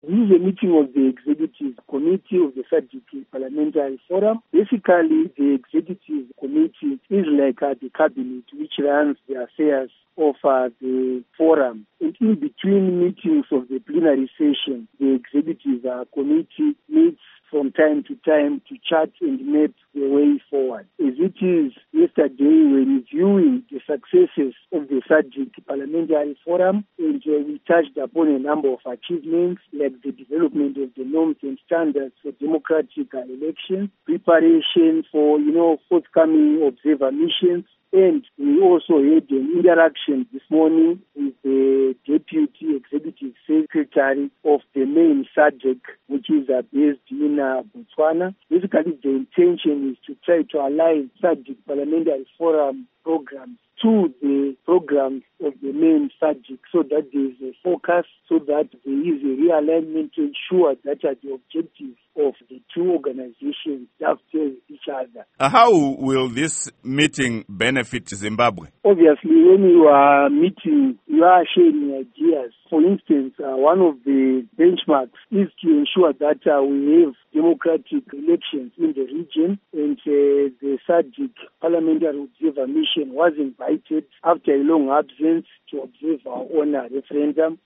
Interview With Innocent Gonese